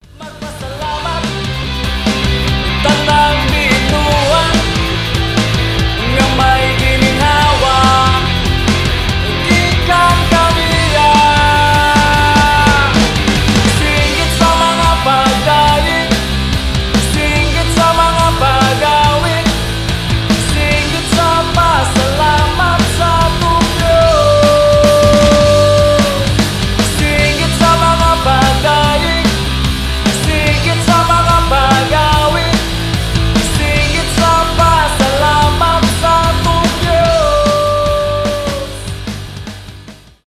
христианские , pop rock